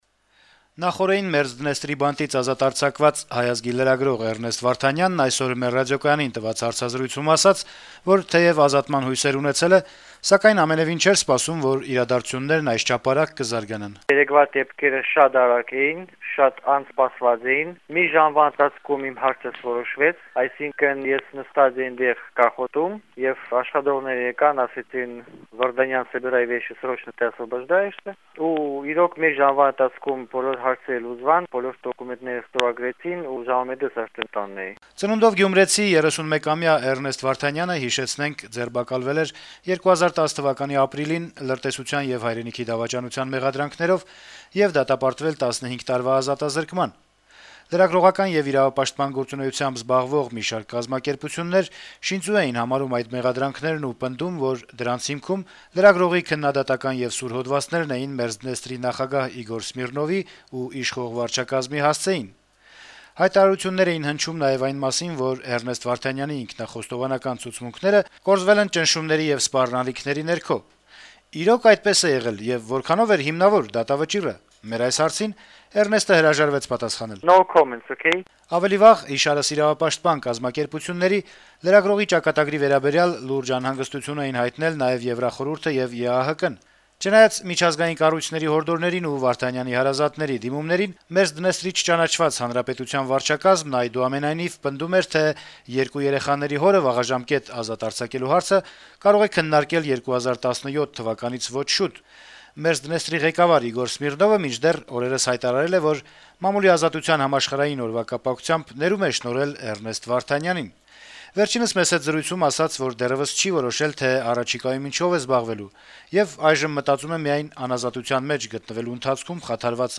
հարցազրույցում